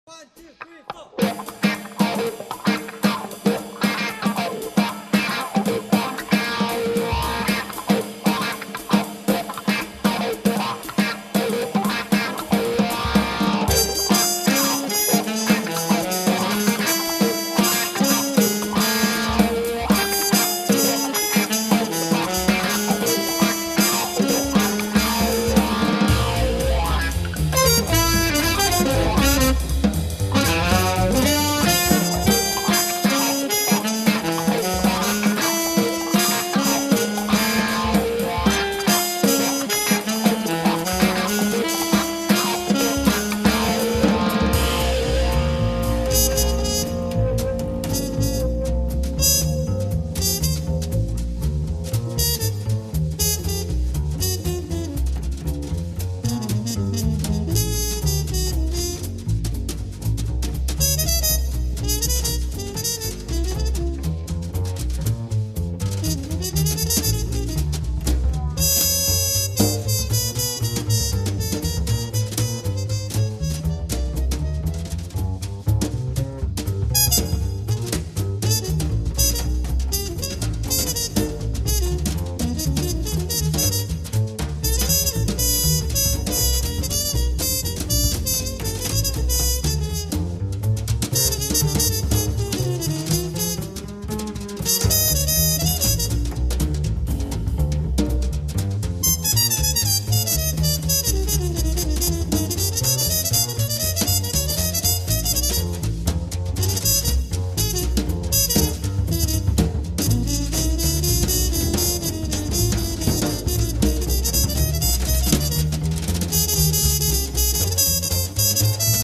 tromba
sax alto e soprano
chitarre
contrabbasso
batteria